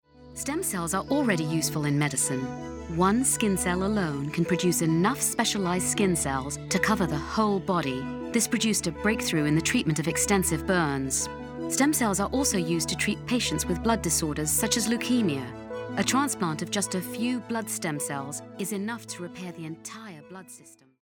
Voice over talent English (British), native speaker. Nonaccent voice. International global vibe.
Sprechprobe: Sonstiges (Muttersprache):